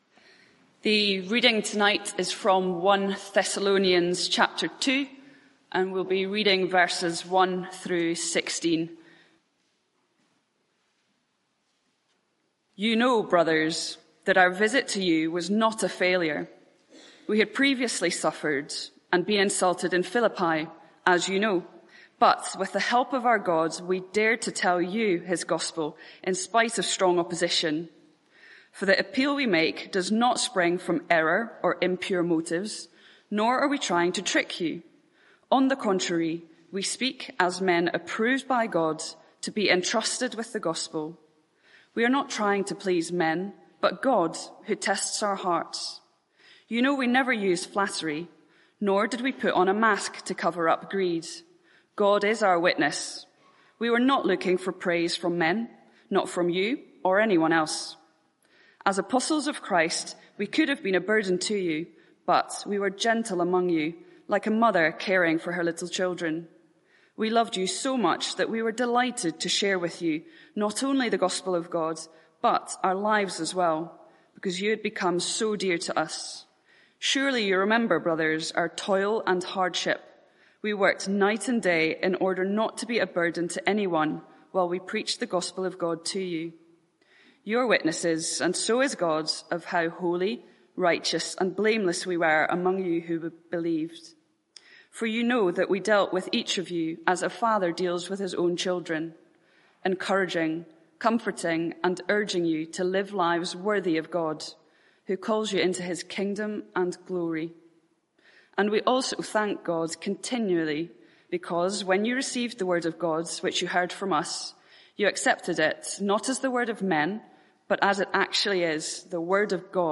Media for 6:30pm Service on Sun 06th Aug 2023 18:30
Sermon (audio)